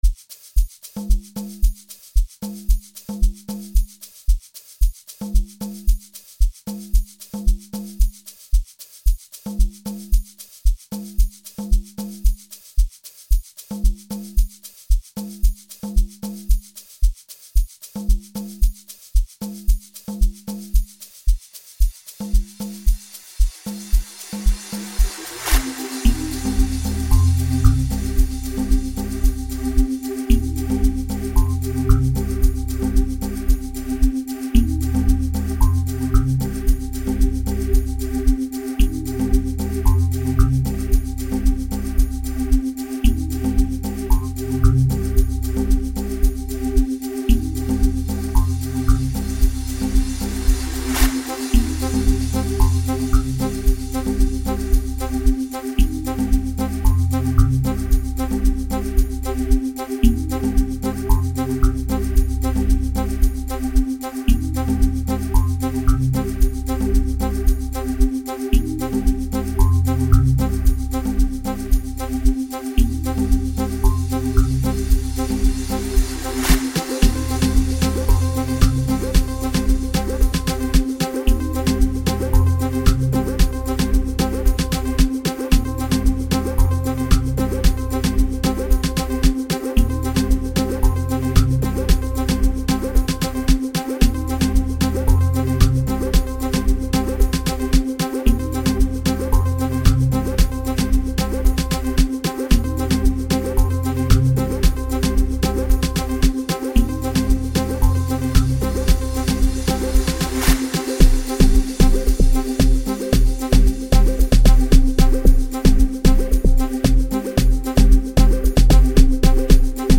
dance mix song